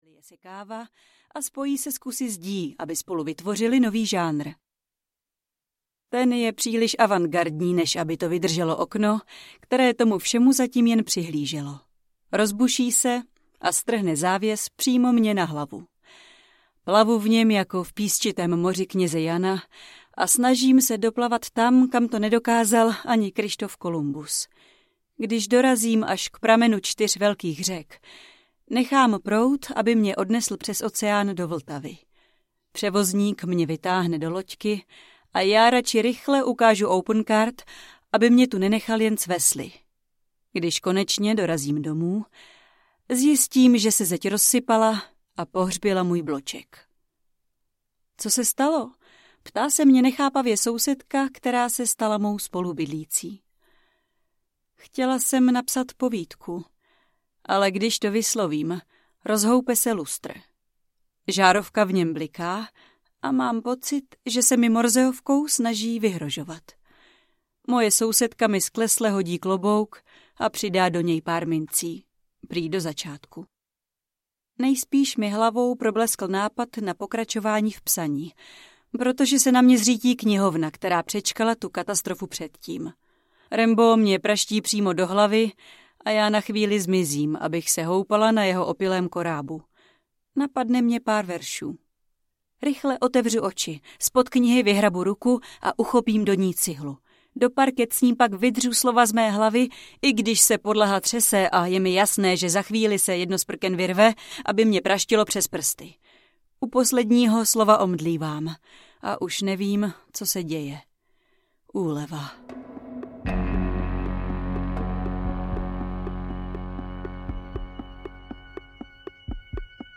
Na kusy audiokniha
Ukázka z knihy